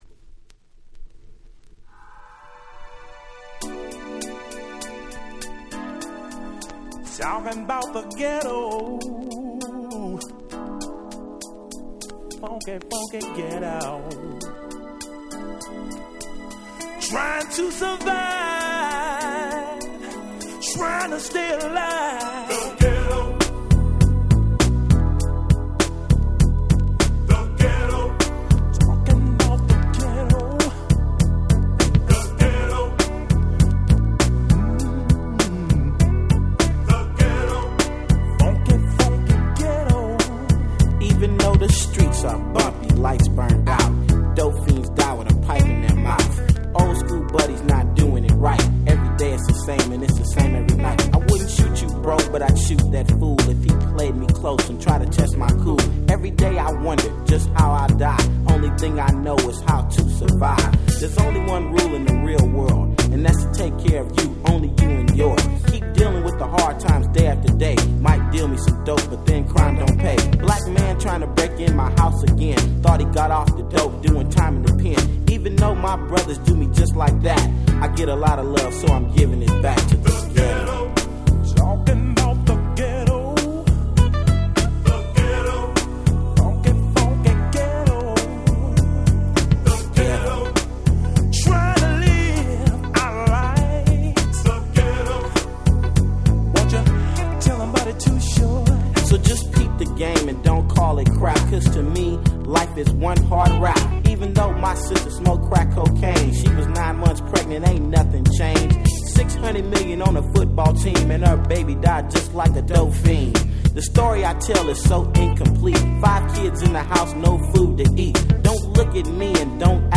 90' Smash Hit Hip Hop !!
90's West Coast Hip Hop Super Classics !!